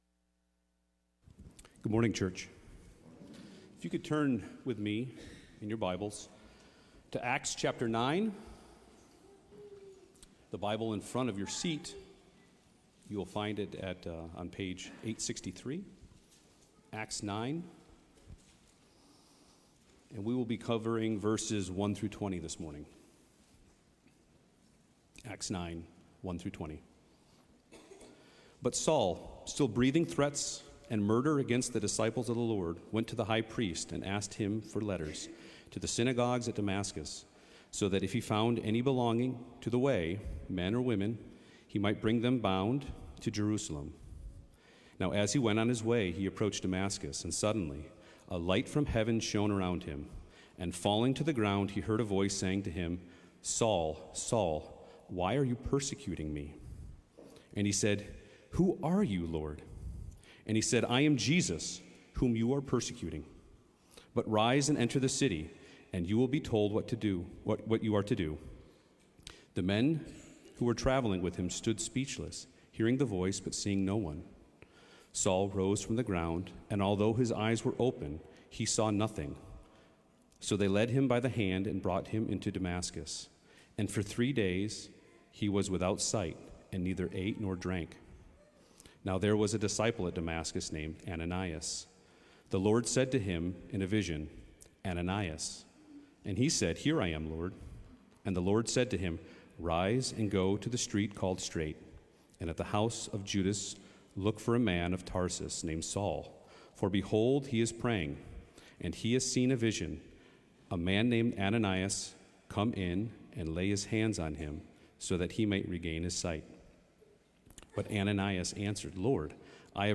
Sermons through the book of Acts